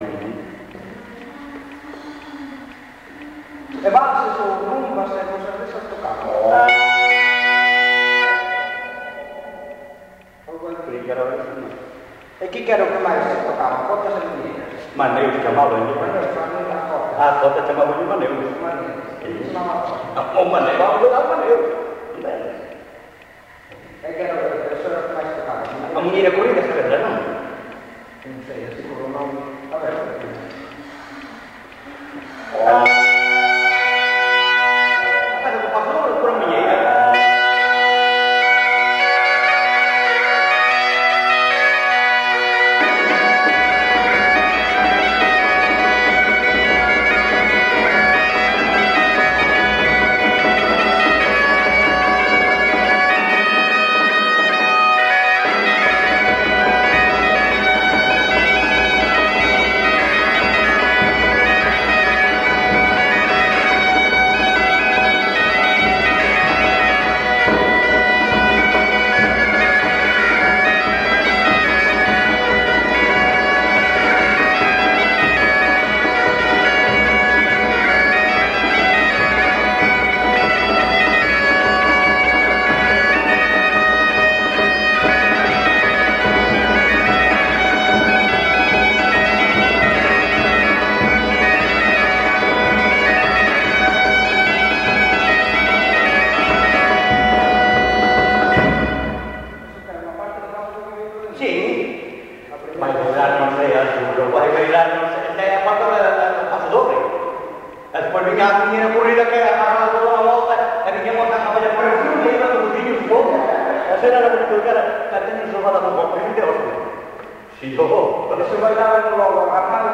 Concello: Carballo.
Tipo de rexistro: Musical
Soporte orixinal: Casete
Xénero: Pasodobre, Muiñeira
Instrumentos: Gaita, Tambor
Nalgún momento intervén unha voz feminina que non temos identificada.